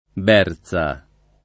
berza [ b $ r Z a ]